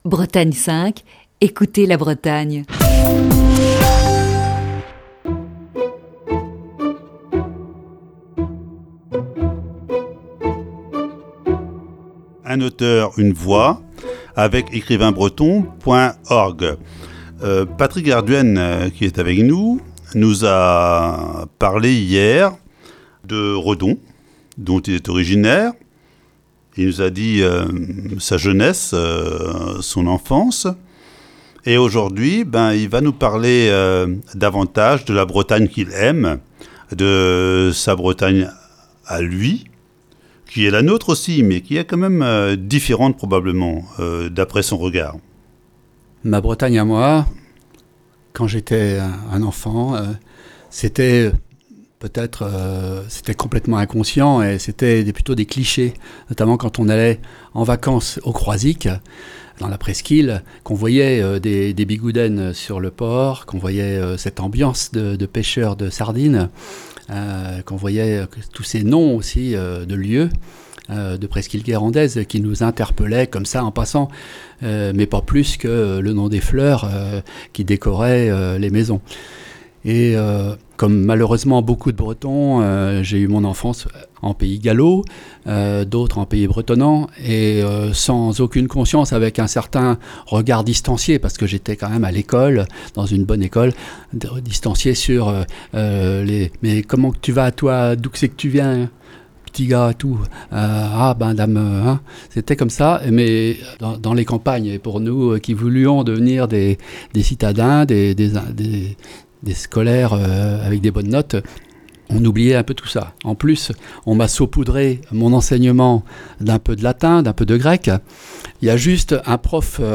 Ce matin, voici la deuxième partie de cet entretien.